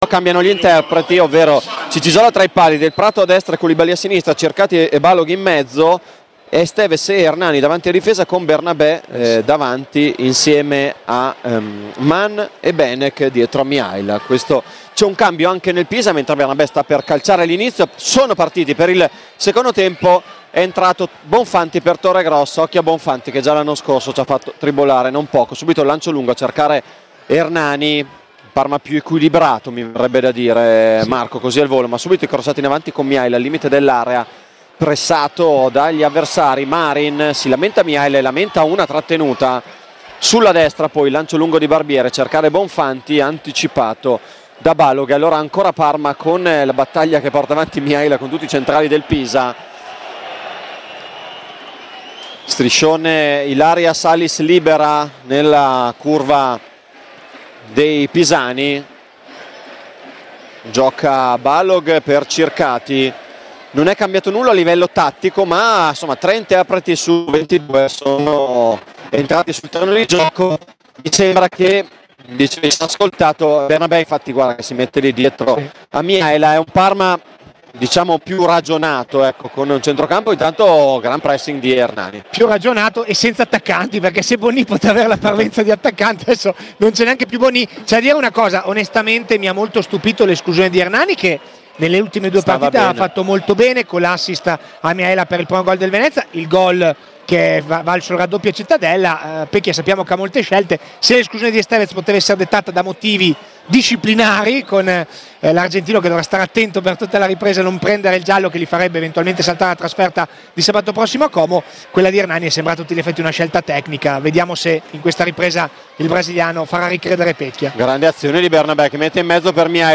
Radiocronaca
Commento tecnico